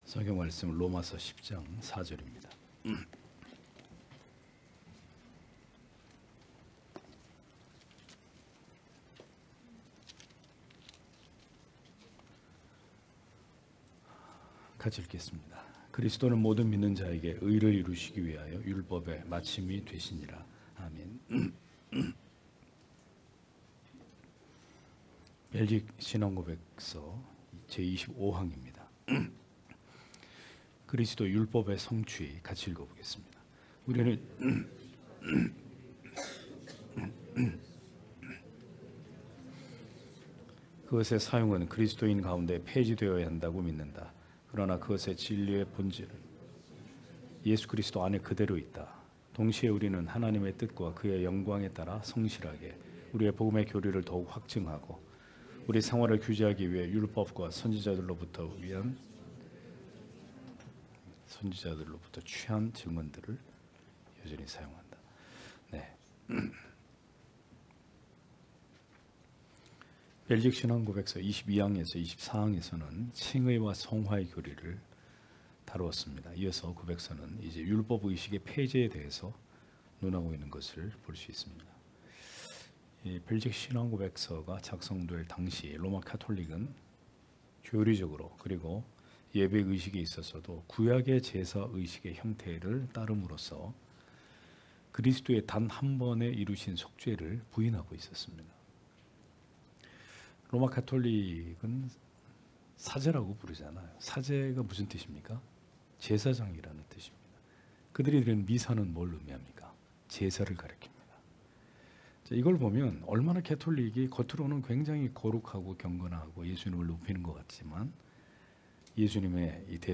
주일오후예배 - [벨직 신앙고백서 해설 28] 제25항 그리스도, 율법의 성취(롬10장4절)